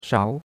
shao2.mp3